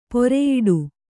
♪ poreyiḍu